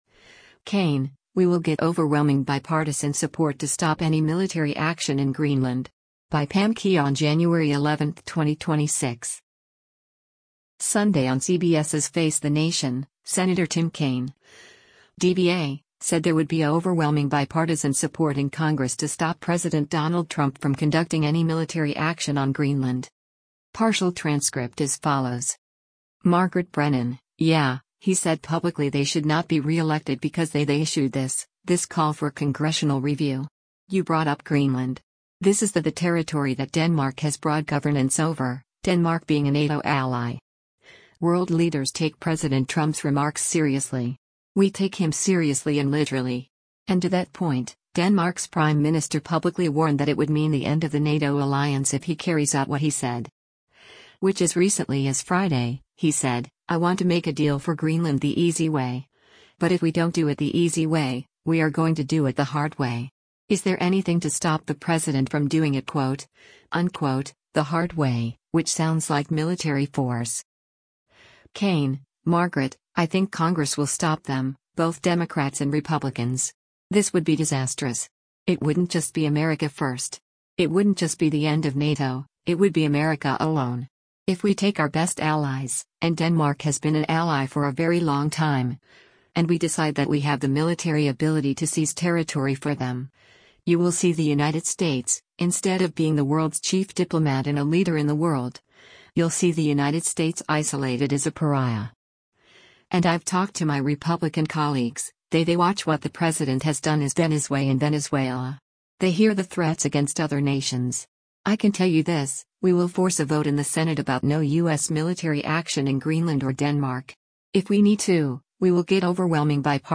Sunday on CBS’s “Face the Nation,” Sen. Tim Kaine (D-VA) said there would be “overwhelming bipartisan support” in Congress to stop President Donald Trump from conducting any military action on Greenland.